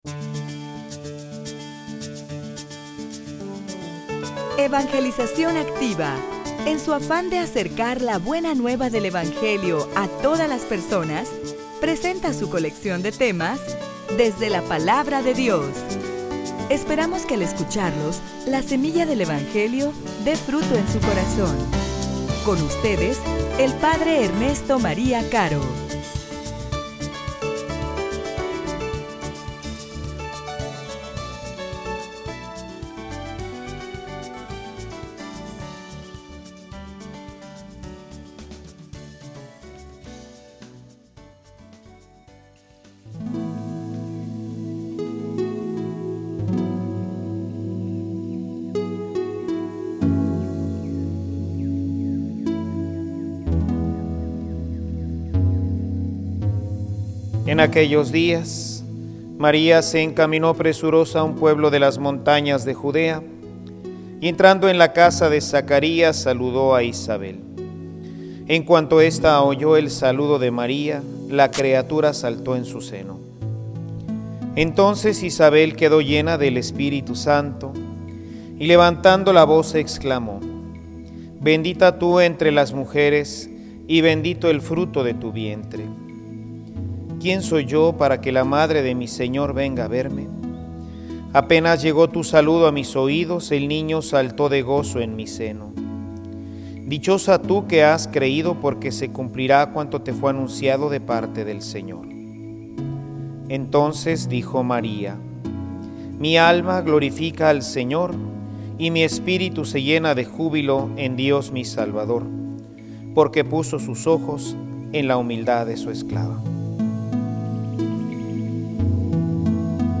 homilia_Gudalupe_una_fiesta_de_esperanza.mp3